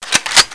assets/nx/nzportable/nzp/sounds/weapons/mg42/charge.wav at 1ef7afbc15f2e025cfd30aafe1b7b647c5e3bb53
charge.wav